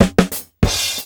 FILL 11   -L.wav